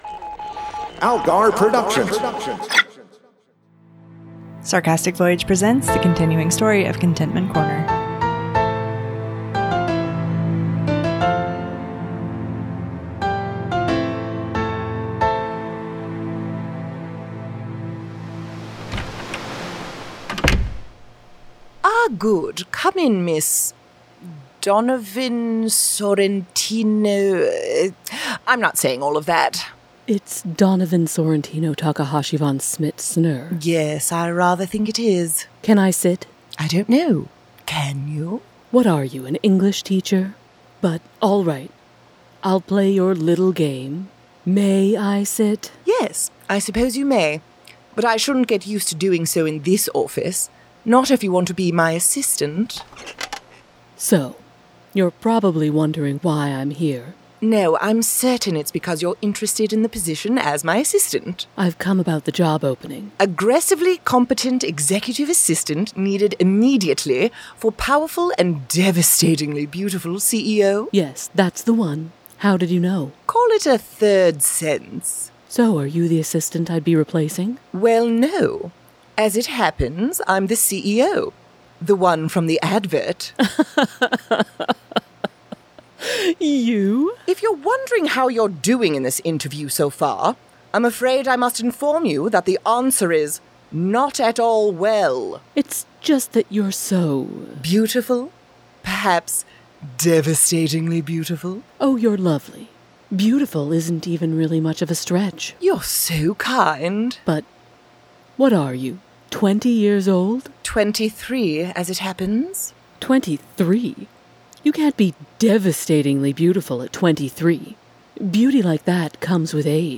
1 New interview | Elisabeth Dermot Walsh reflects on the end of Doctors | Soap Scoop podcast exclusive 17:12